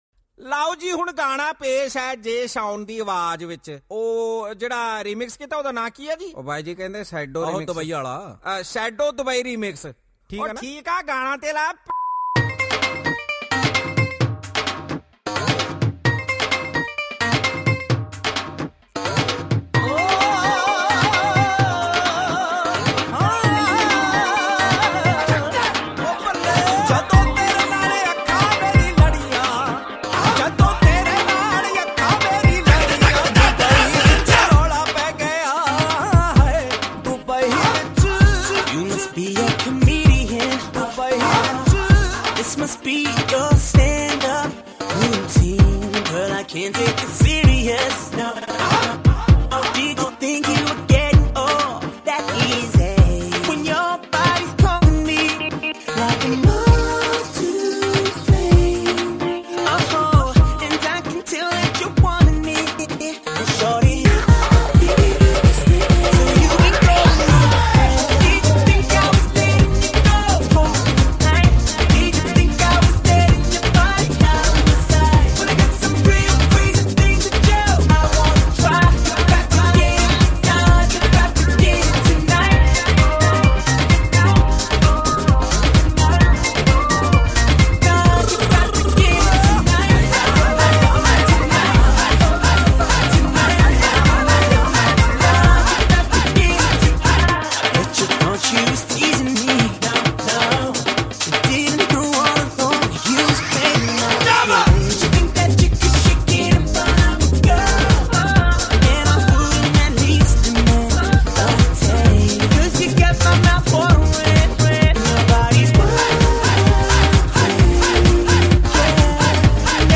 DJ Remix Mp3 Songs